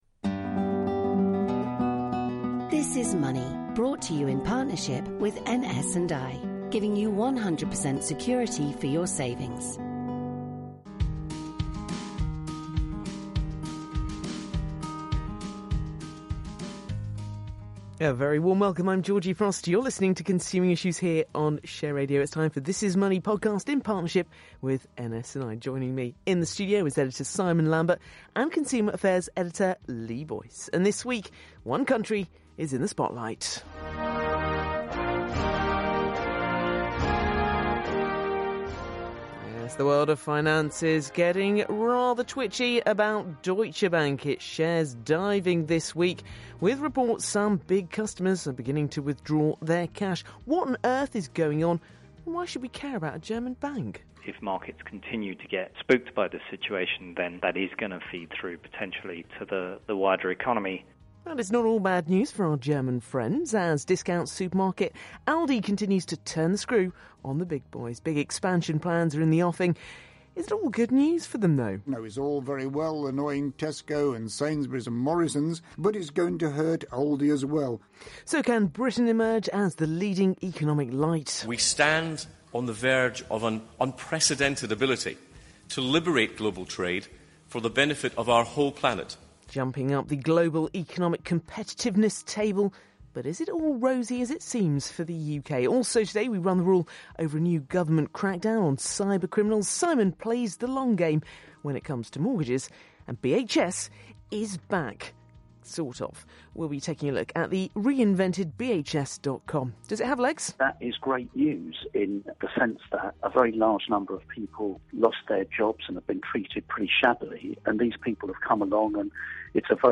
Business News, Business, Investing, News